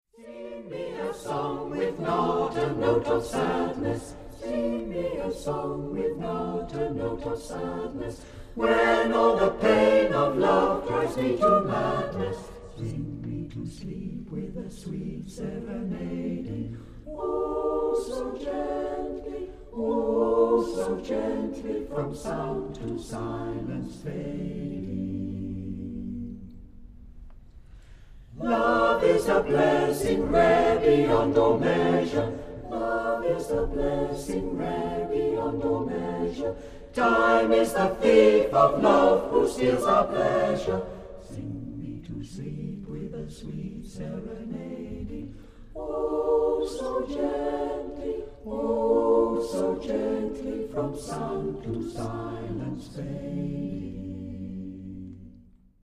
Musyck Anon, a cappella choir in Evesham, Worcestershire and Gloucestershire